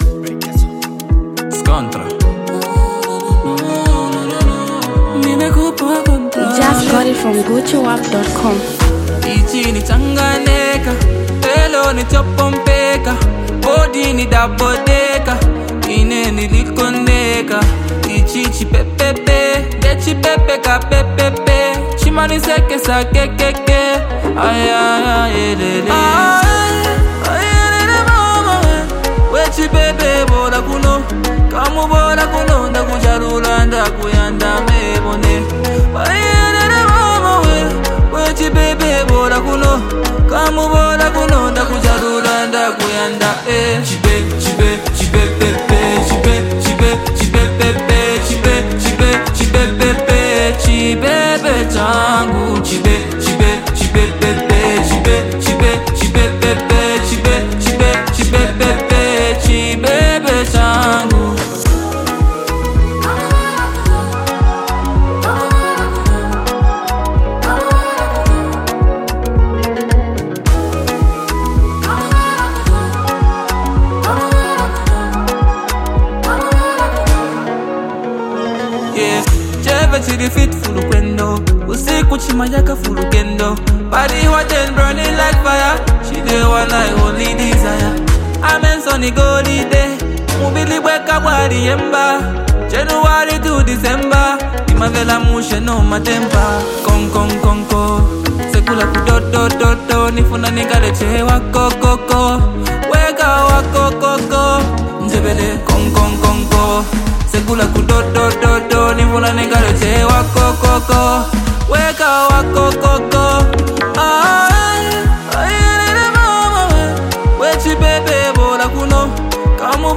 Zambian Mp3 Music